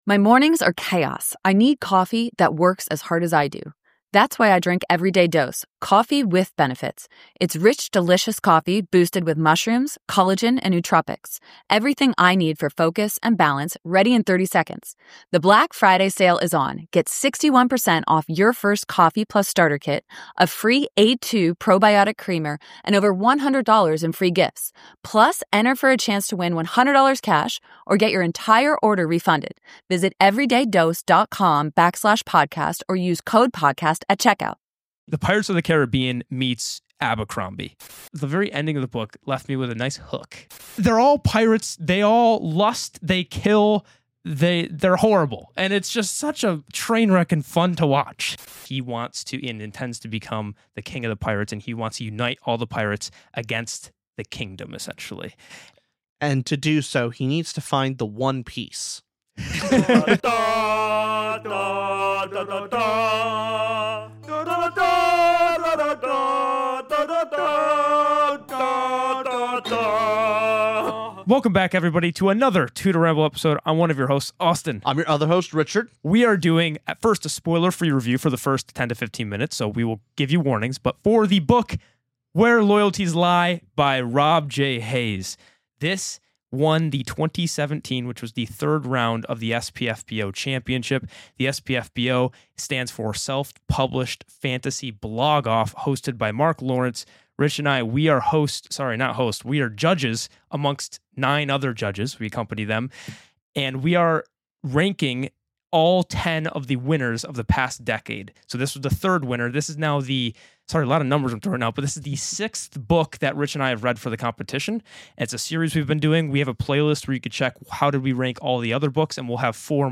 2 guys talking about books, movies, & shows.